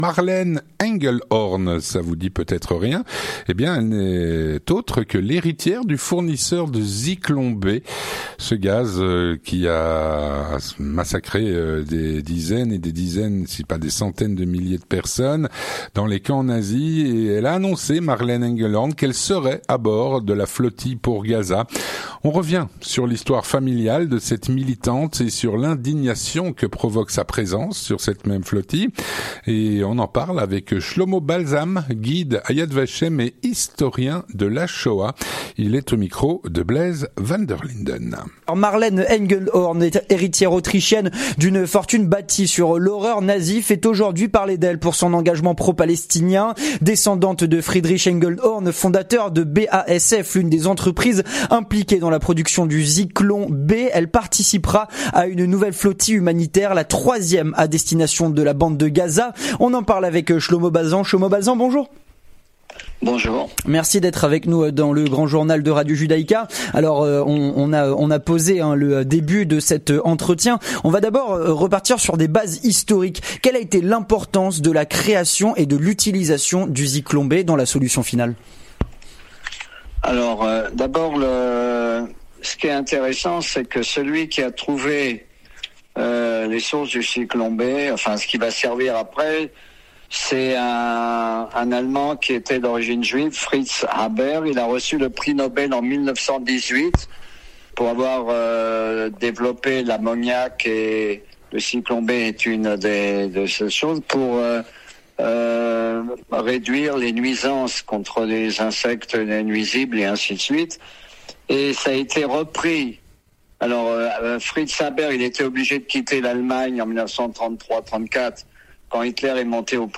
L'entretien du 18H - L'héritière du fournisseur de Zyklon B aux camps nazis fait partie de la Flotille pour Gaza.